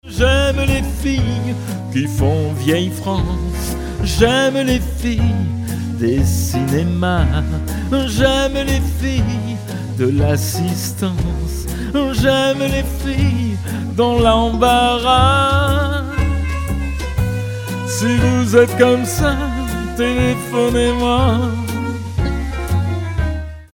Version chantée